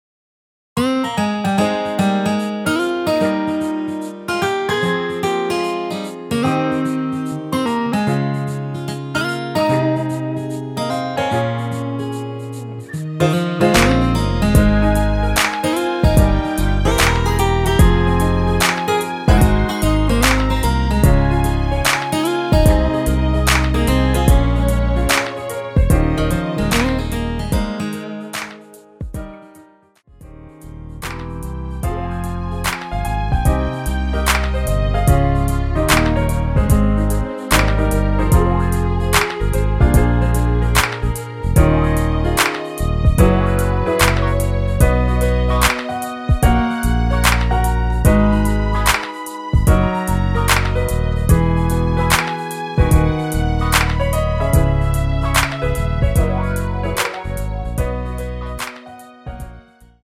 원키에서(+5)올린 MR입니다.
Ab
앞부분30초, 뒷부분30초씩 편집해서 올려 드리고 있습니다.